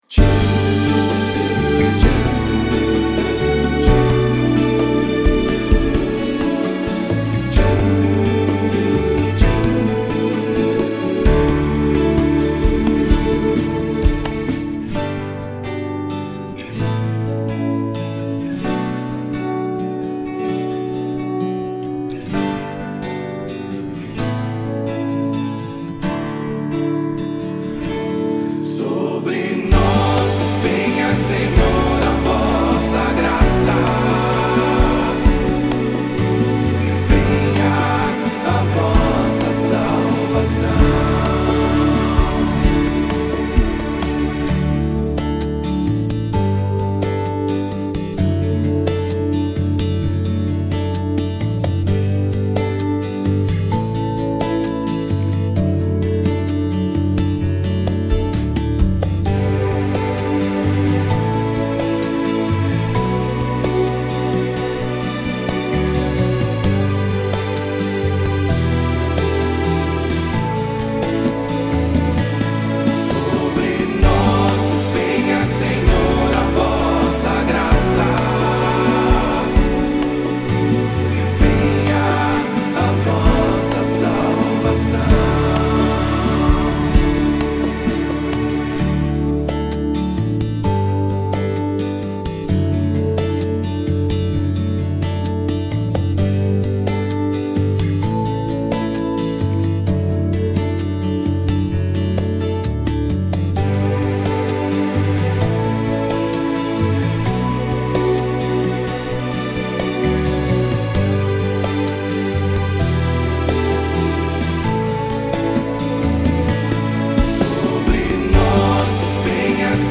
PlayBack (música)